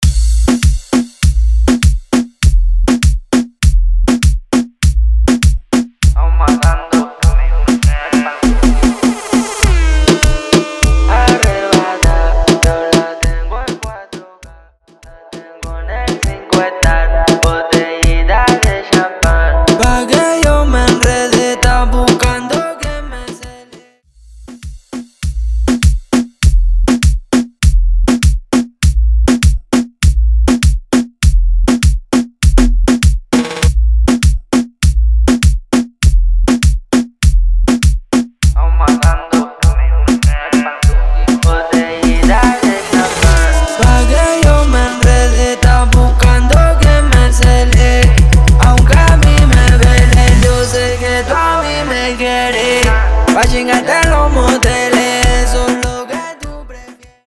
Intro Dirty, Intro Hype Dirty